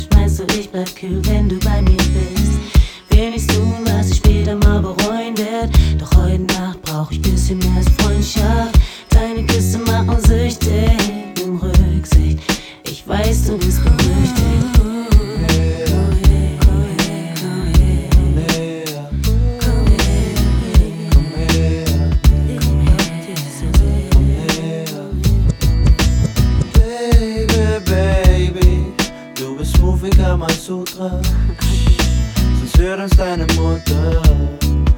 Жанр: Иностранный рэп и хип-хоп / Рок / Рэп и хип-хоп